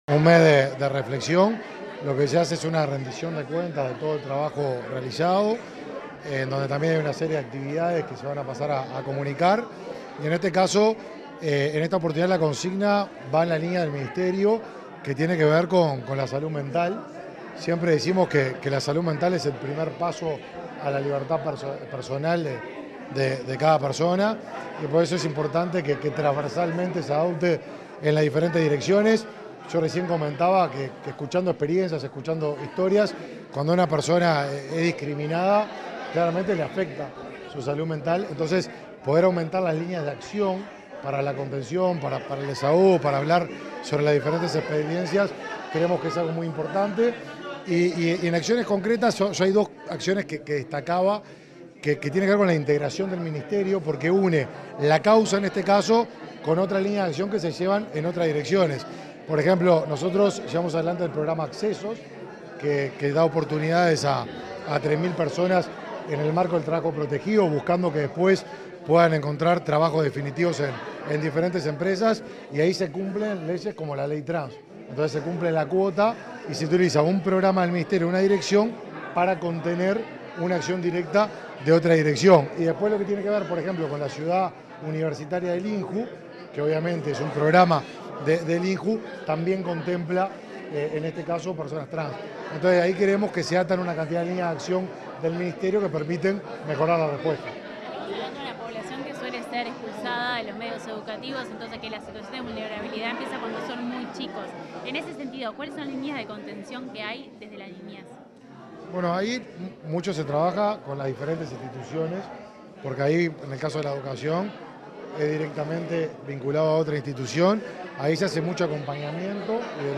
Declaraciones a la prensa del ministro del Mides, Martín Lema
Declaraciones a la prensa del ministro del Mides, Martín Lema 01/09/2023 Compartir Facebook X Copiar enlace WhatsApp LinkedIn Tras participar en el lanzamiento del Mes de la Diversidad, este 1.° de setiembre, el titular del Ministerio de Desarrollo Social (Mides), Martín Lema, realizó declaraciones a la prensa.